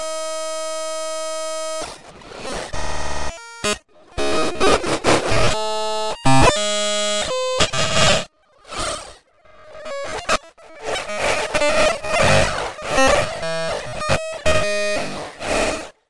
描述：所有系统停止运行（缓慢发展的故障）
Tag: 毛刺 错位 漂亮的 噪声 噪声 配音 无用